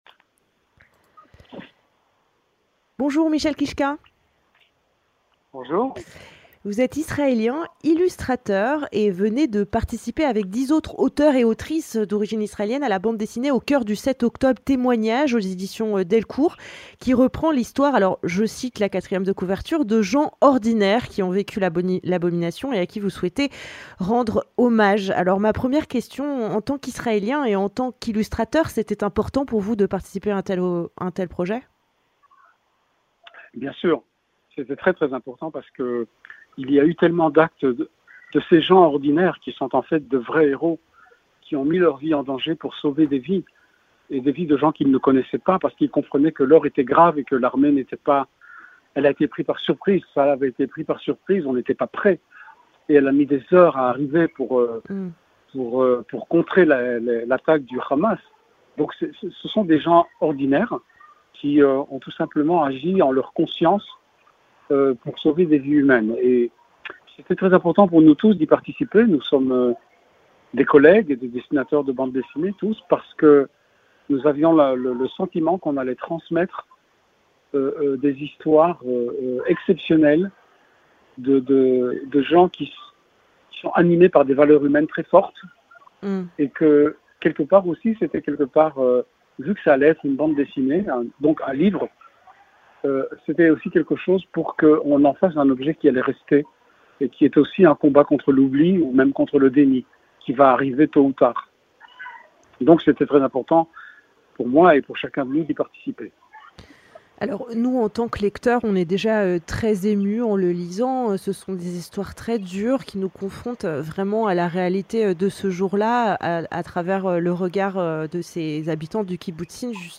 Avec Michel Kichka, célèbre illustrateur israélien, nous a fait le plaisir de venir présenter cette nouvelle BD qu'il a illustrée.